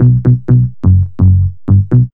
1908L B-LOOP.wav